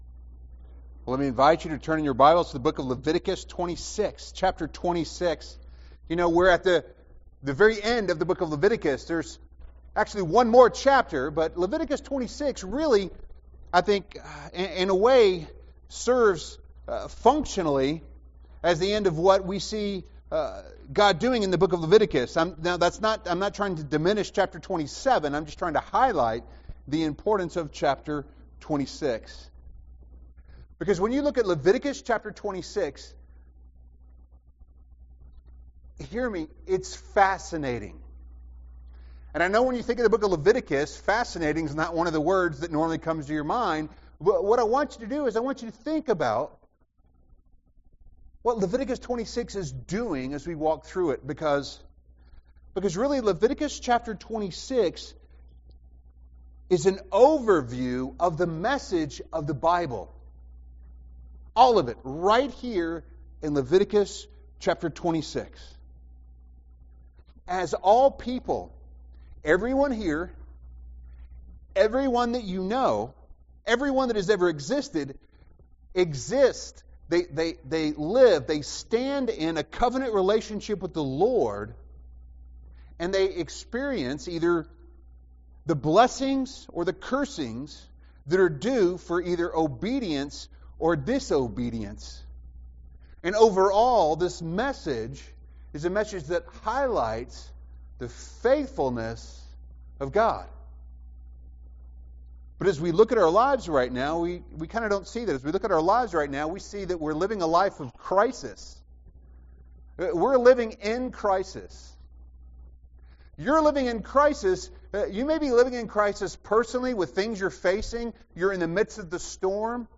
Sermons Archive - New Beginnings Bible Church